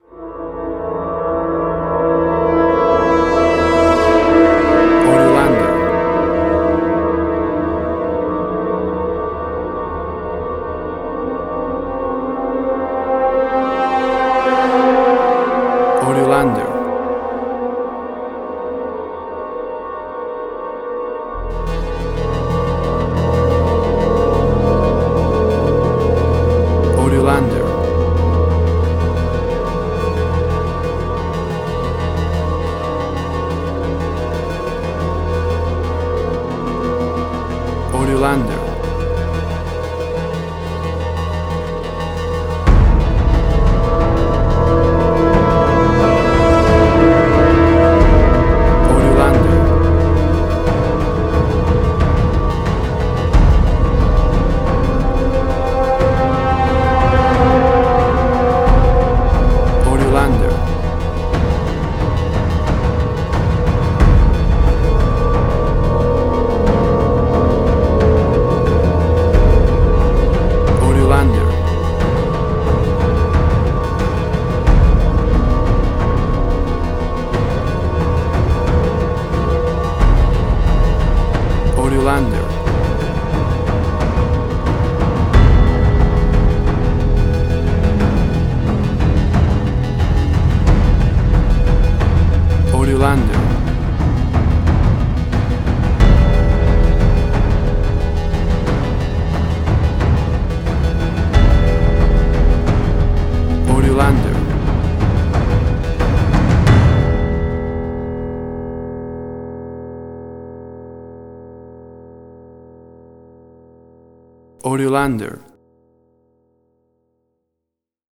Suspense, Drama, Quirky, Emotional.
WAV Sample Rate: 16-Bit stereo, 44.1 kHz
Tempo (BPM): 90